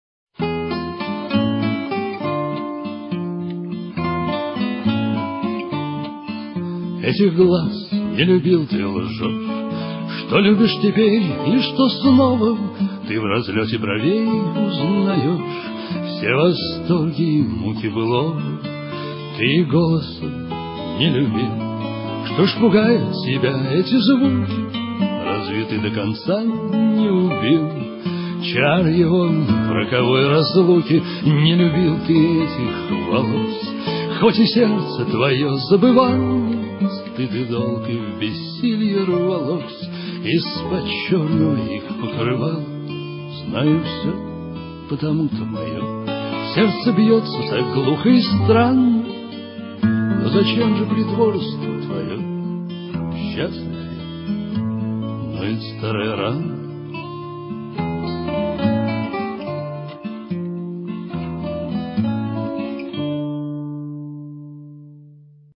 Песня «Лилит» на стихи Михаила Фромана «Самая старая песня»: